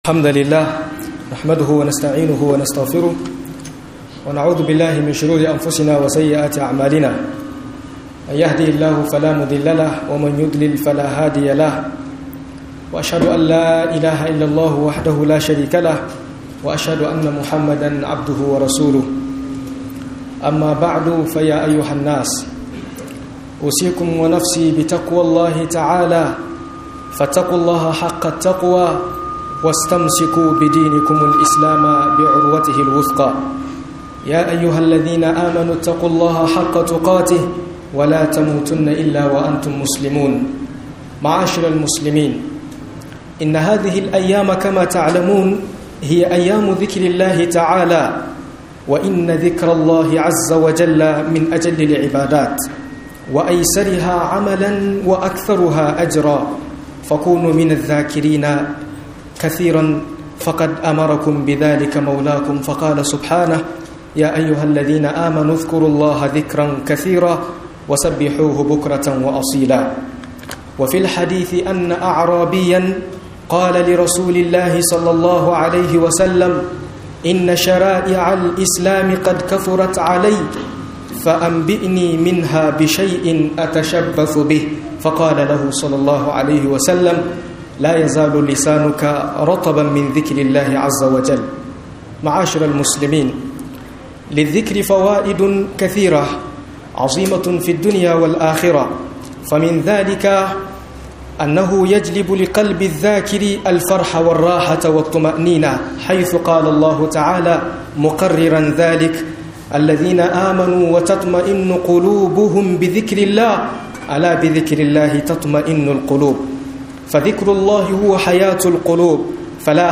Fa'idodin Zikiri - MUHADARA